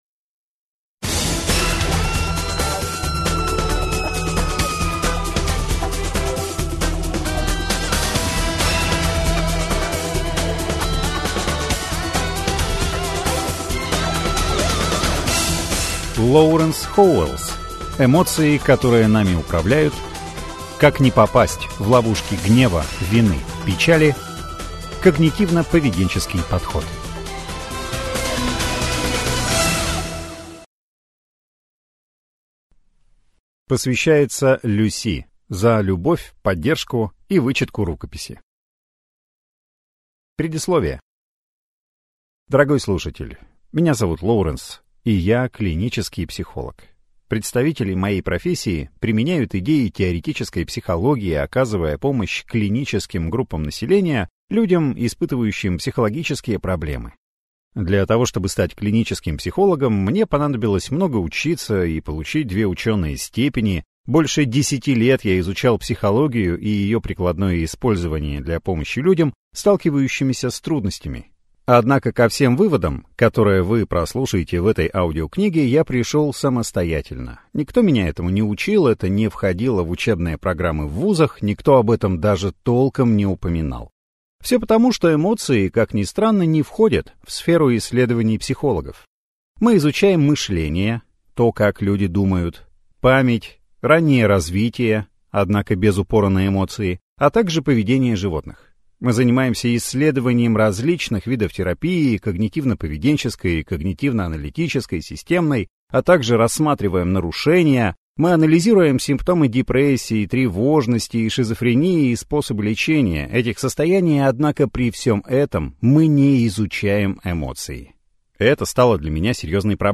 Аудиокнига Эмоции, которые нами управляют: Как не попасть в ловушки гнева, вины, печали. Когнитивно-поведенческий подход | Библиотека аудиокниг